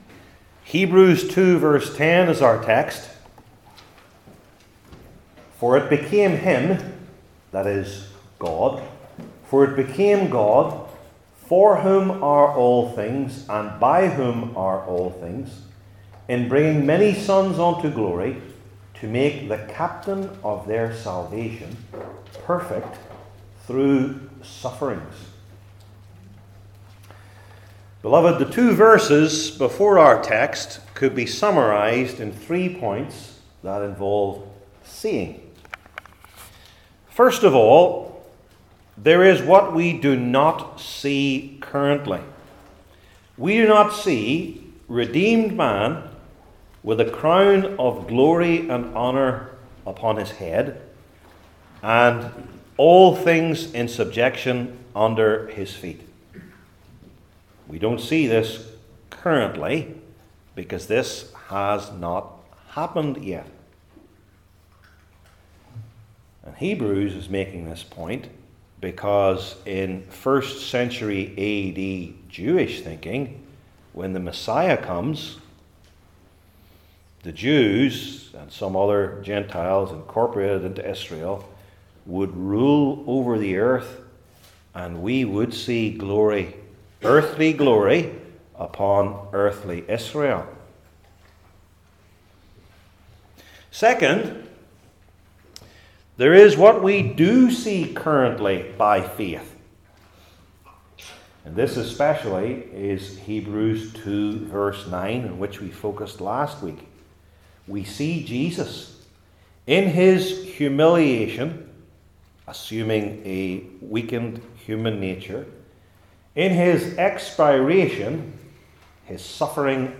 Hebrews 2:10 Service Type: New Testament Sermon Series I. God’s Goal for His People II.